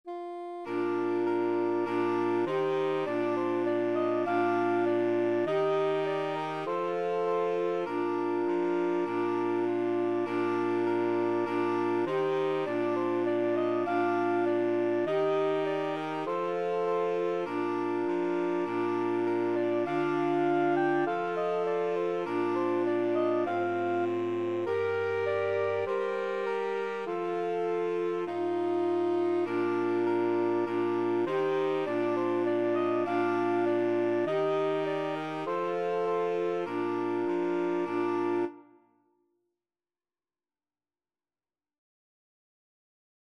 4/4 (View more 4/4 Music)
Saxophone Quartet  (View more Easy Saxophone Quartet Music)
Traditional (View more Traditional Saxophone Quartet Music)